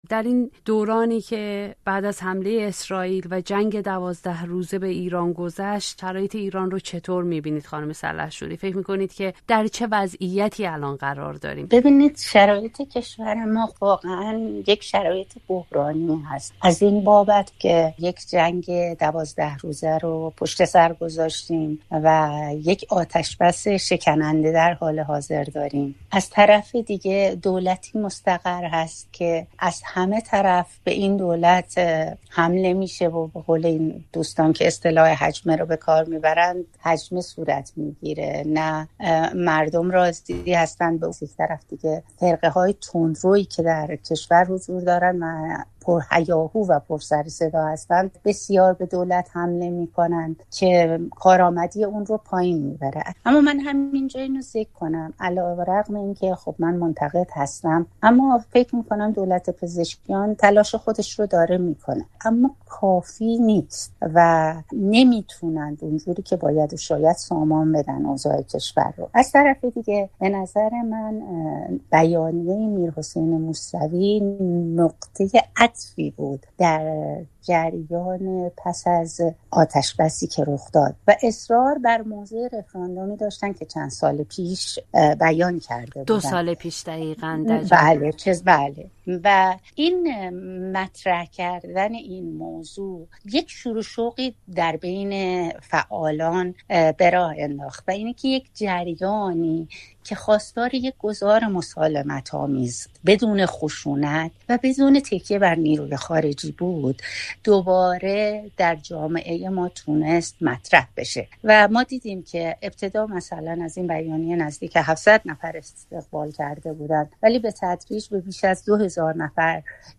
پروانه سلحشوری، نمایندهٔ سابق مجلس، به رادیوفردا می‌گوید شرایط ایران بعد از جنگ ۱۲ روزه و آتش‌بسِ شکننده، بحرانی است و اگر مکانیسم ماشه فعال شود،‌ ایران فلج خواهد شد